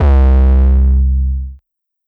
Mr Lonely 808.wav